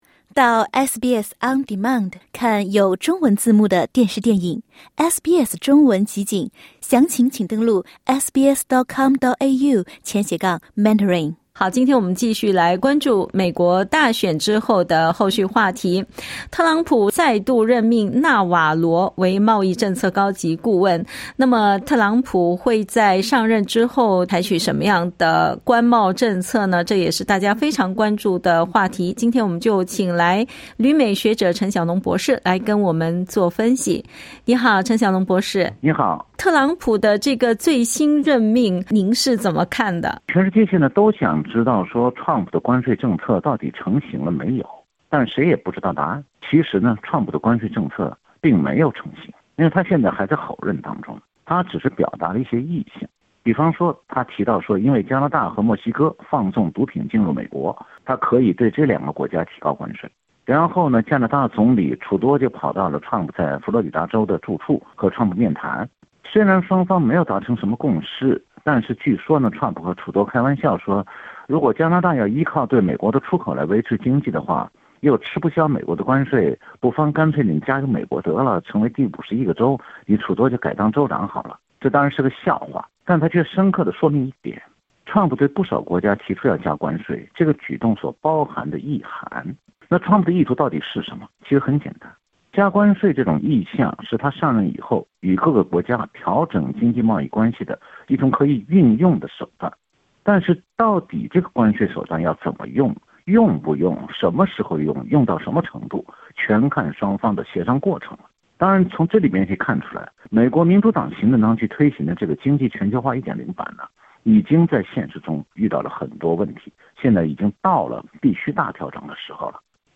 特朗普就职后所实施的政策会否影响到正在解冻的澳中贸易关系？（点击音频收听详细采访）